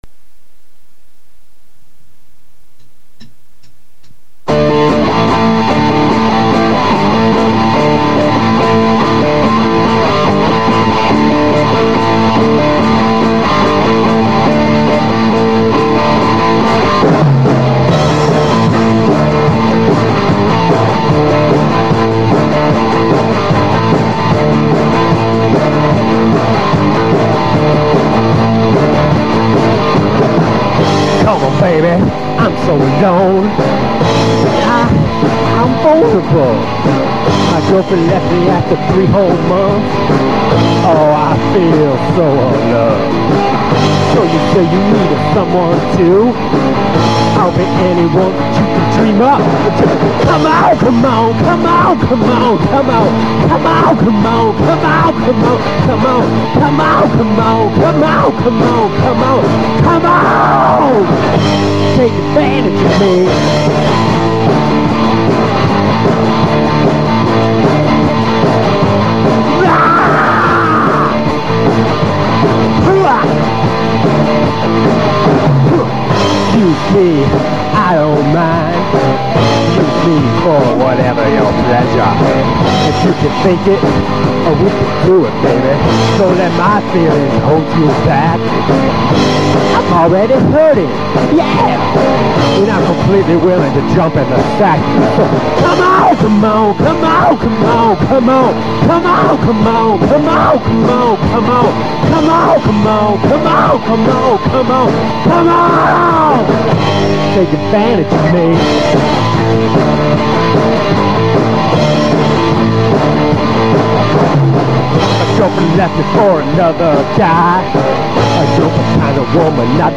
From a St. Louis basement comes a lo-fi gem.